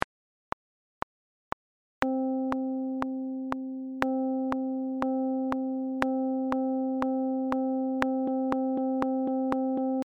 Note values in 4/4 time signature example
Note-values-in-44-audio-example.mp3